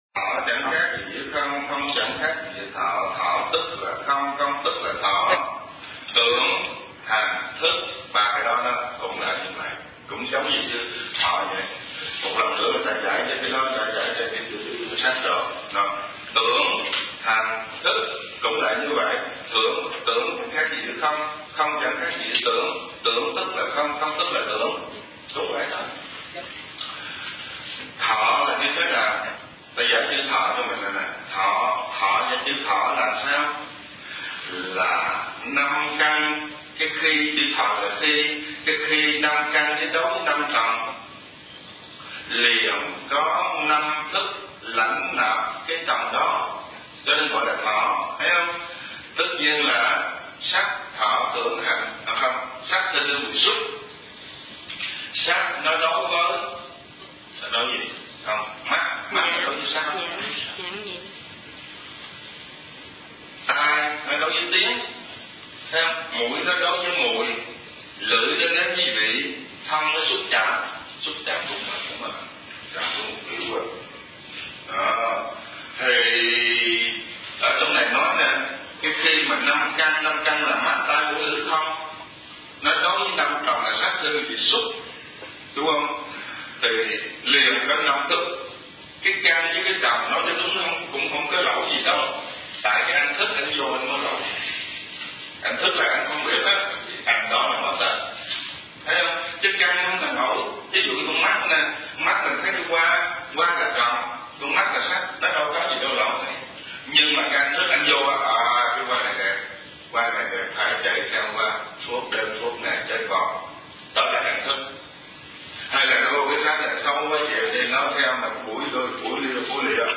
Kinh Giảng Nhị Khóa Hiệp Giải